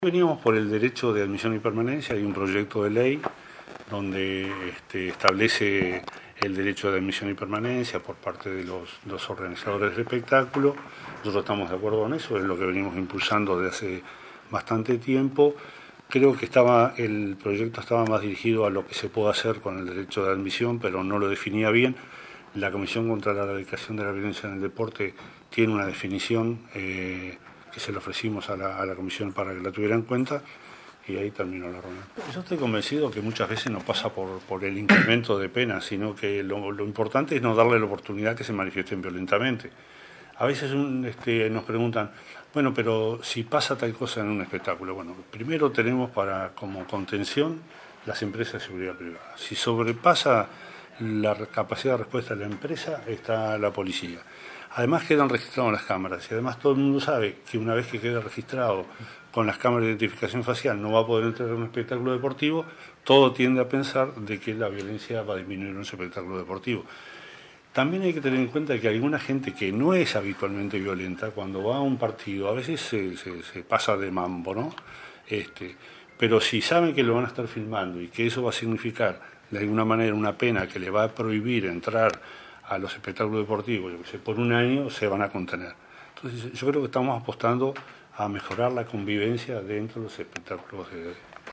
“Estamos apostando a mejorar la convivencia dentro de los espectáculos deportivos”, puntualizó el subsecretario del Interior, Jorge Vázquez, tras concurrir a la comisión especial de deporte del Parlamento. Habló con la prensa de la importancia de las cámaras de identificación facial y dijo que la solución no pasa por incrementar penas sino en no darle la oportunidad a los espectadores de que se manifiesten violentamente.